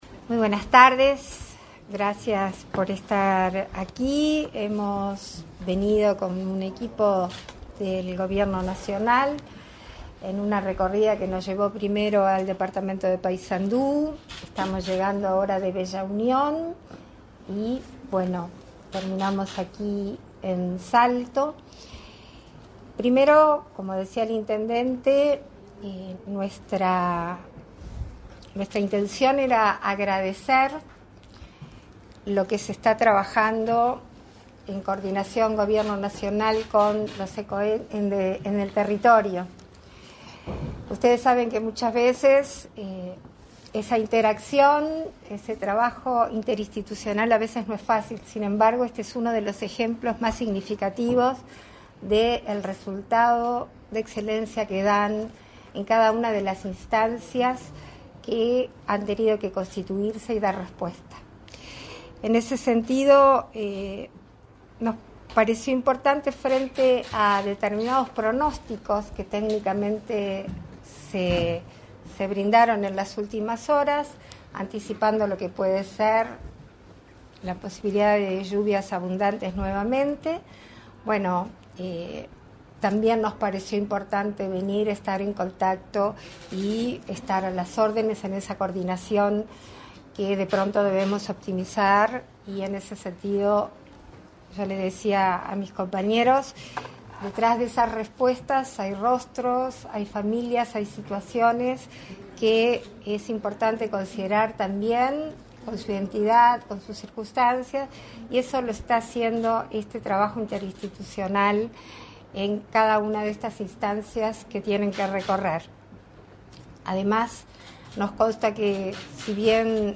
Conferencia de prensa de Beatriz Argimón y Álvaro Delgado tras reunión con el Cecoed de Salto
La presidenta de la República en ejercicio, Beatriz Argimón, el secretario de Presidencia, Álvaro Delgado, y demás autoridades del Gobierno se reunieron, este 21 de noviembre, con el Centro Coordinador de Emergencias Departamentales (Cecoed) de Salto para evaluar la situación de damnificados por inundaciones. Tras el encuentro, se expresaron en conferencia.